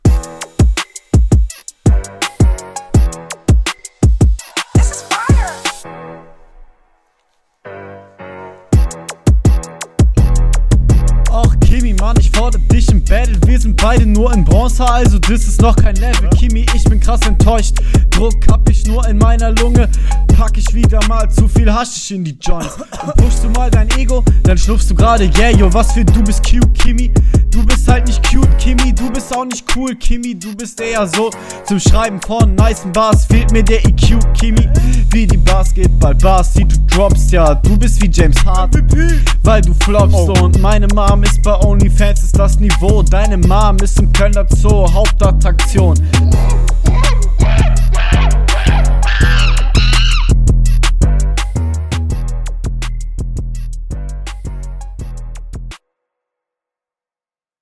Flow: ziemlich standart nicht viel zu sagen Text: Keine wirkliche konter aber gegnerbezug war da …